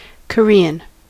Ääntäminen
IPA : /kəˈɹɪən/